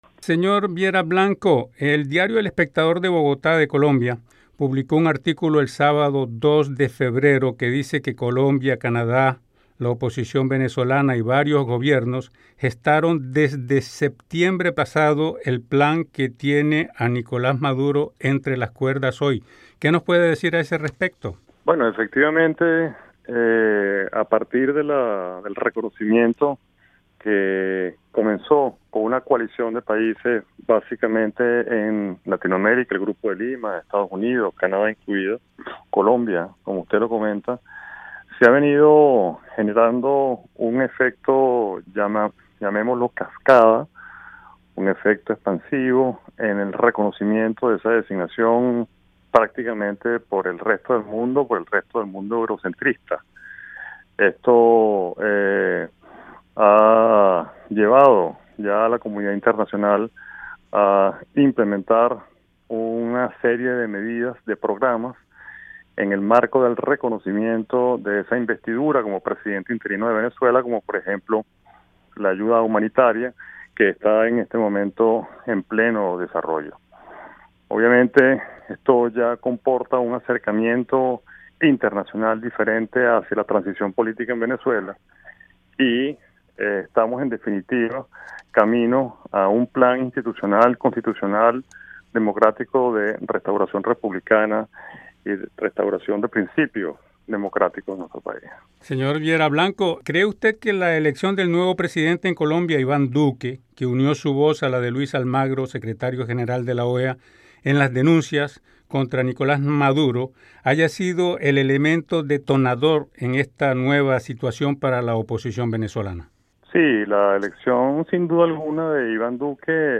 Representante de Guaidó en Canadá conversa con Radio Canadá Internacional